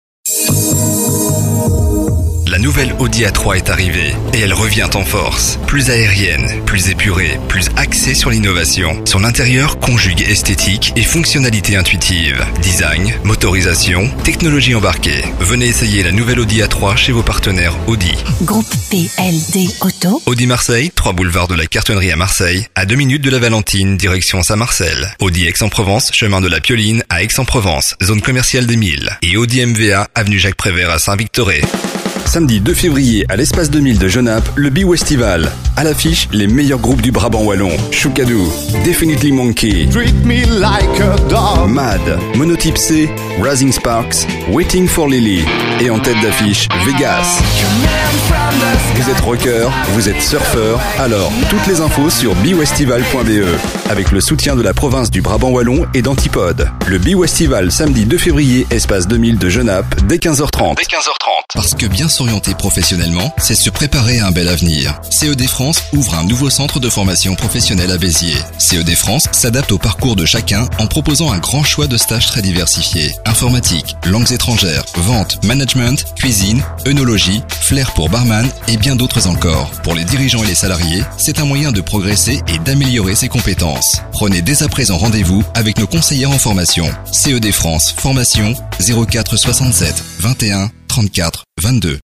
Voix masculine
Voix Mediums